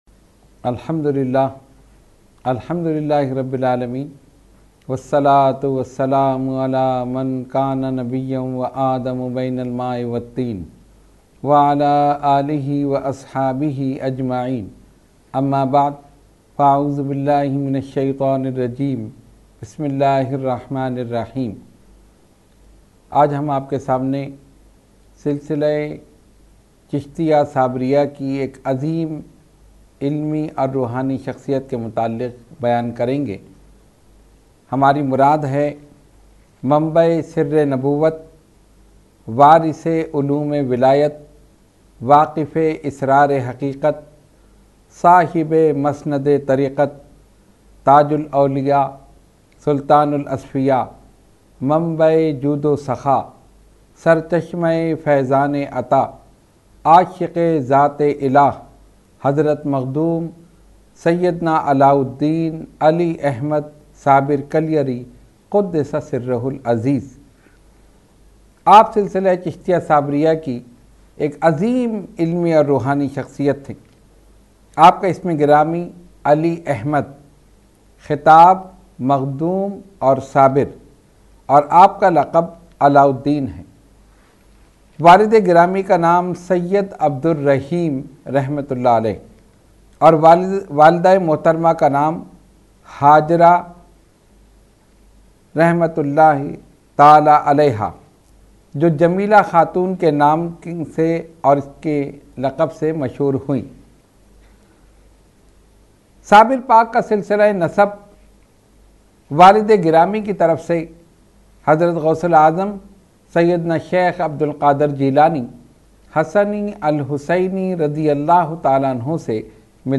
Roohani Tarbiyati Nashist held at Dargah Aliya Ashrafia Ashrafia Ashrafabad Firdous Colony Gulbahar Karachi.
Category : Speech | Language : UrduEvent : Weekly Tarbiyati Nashist